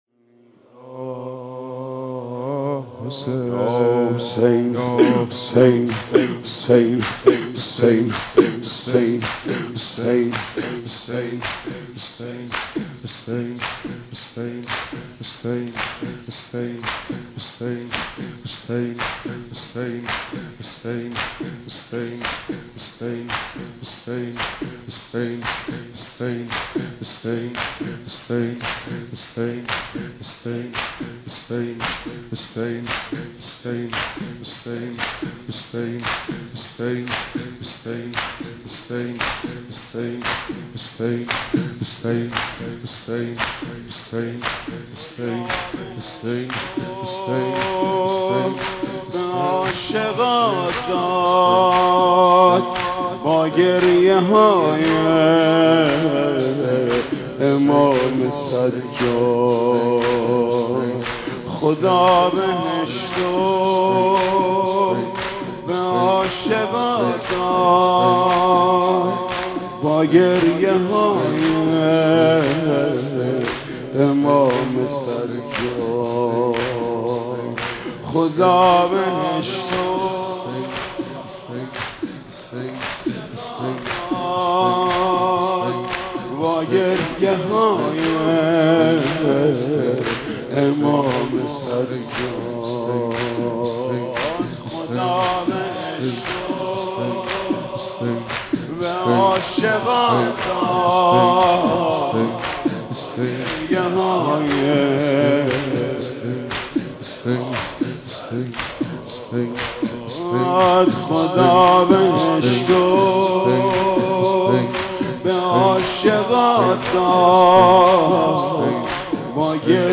به مناسبت سالروز شهادت امام سجاد